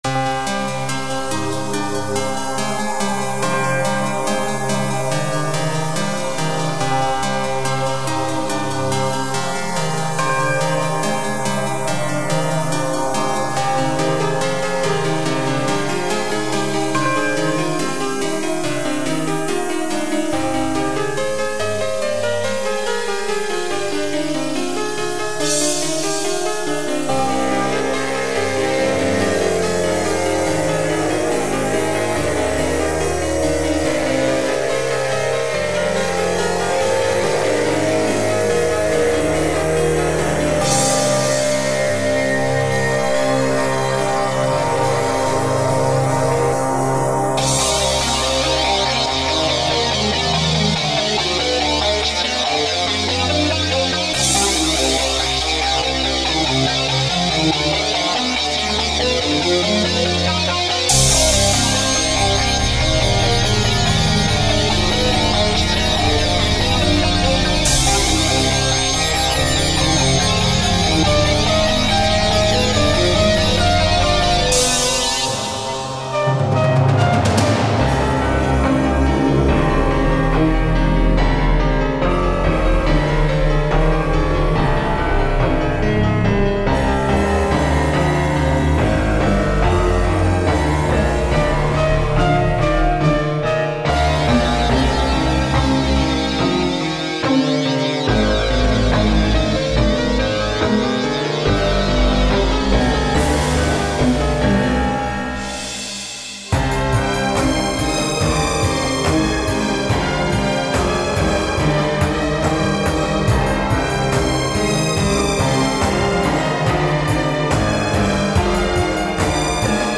27 Вот.. мой новый эксперимент- смесь классики с тяжелым роком.. В треке использованы свои сэмплы, наигранные в FL-8,9 и часть сторонних (ударные и перкуссии). Все собрано в 6-м Дэнсе, потом обработано в Sound Forge 9 + плагин iZotope Ozone 3.